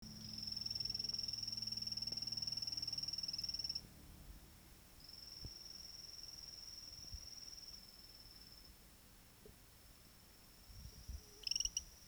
7615Epipedobates anthonyi.mp3